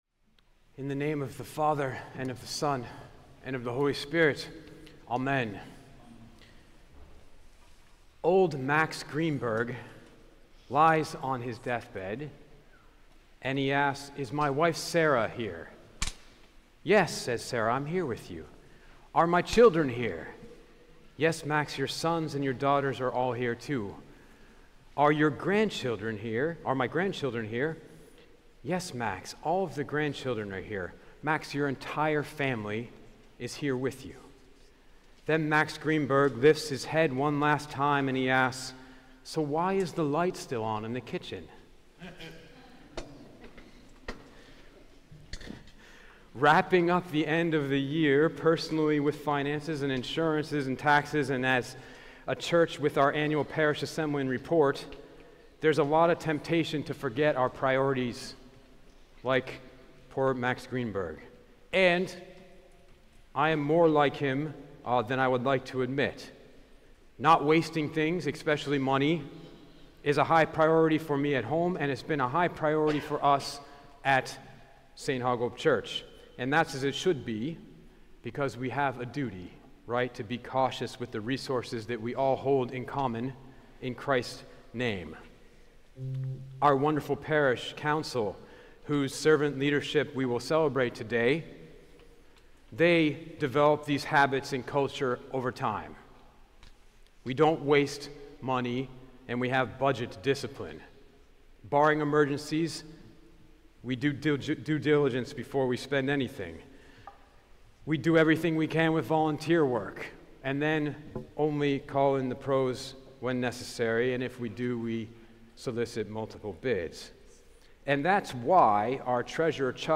Podcasts Sermons St. Hagop Armenian Church